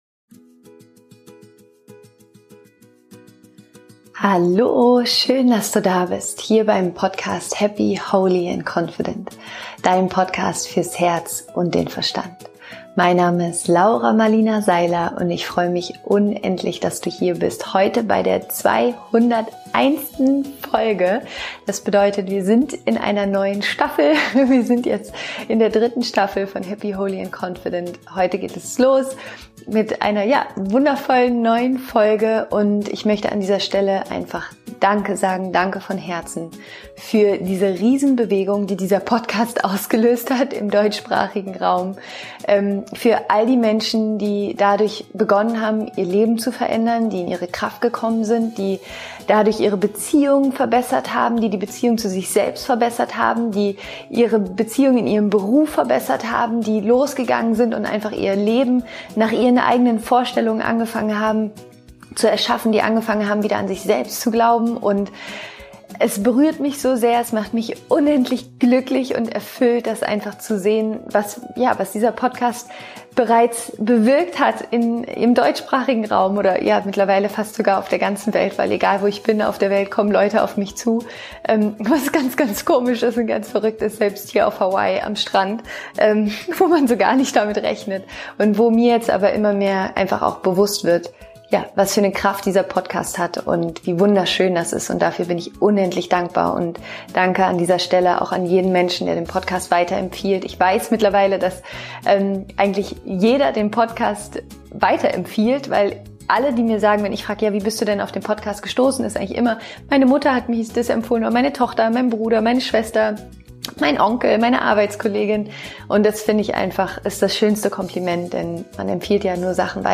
Heute wartet eine sehr kraftvolle Solofolge von mir auf dich, in der ich über die drei wichtigsten gedanklichen Shifts für mehr Liebe, Fülle und Erfolg in deinem Leben spreche.